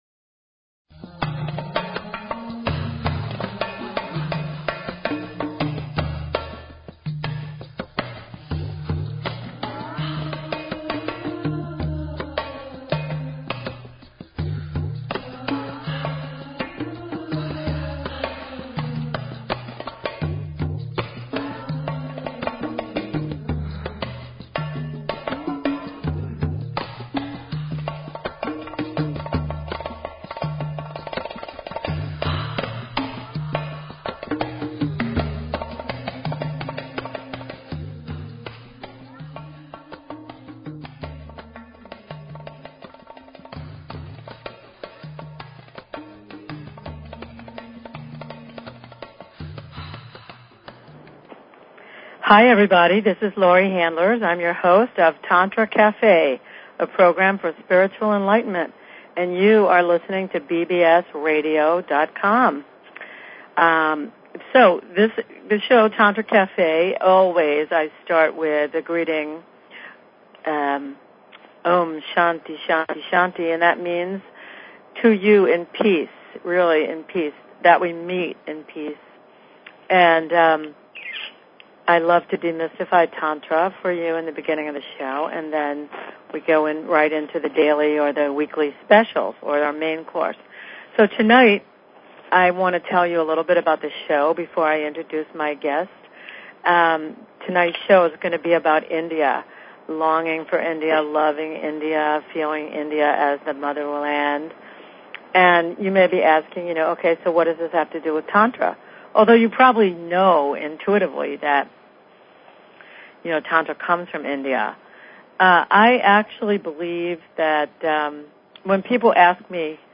Talk Show Episode, Audio Podcast, Tantra_Cafe and Courtesy of BBS Radio on , show guests , about , categorized as